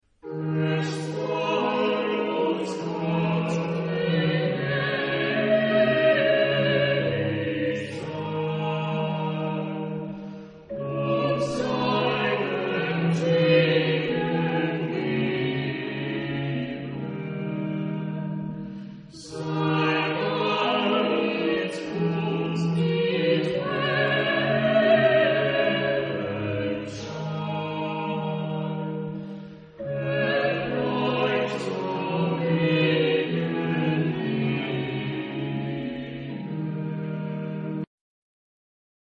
Genre-Style-Form: Sacred ; Baroque ; Chorale
Type of Choir: SATB  (4 mixed voices )
Instrumentation: Chamber orchestra